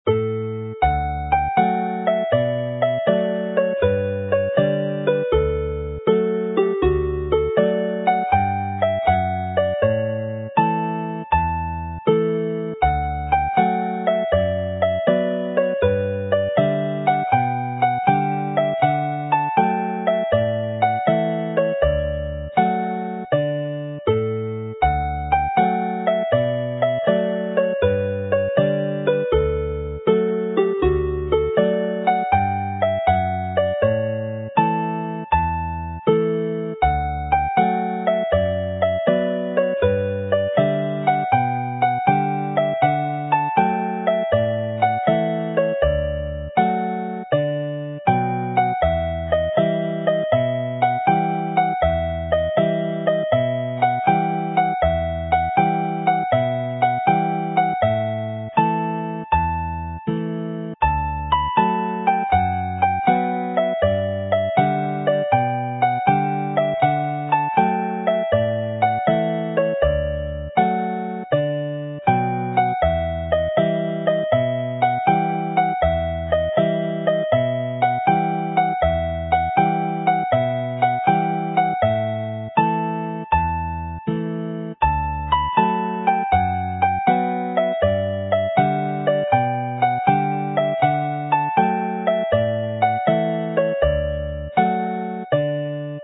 araf / slow